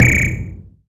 whistle2.ogg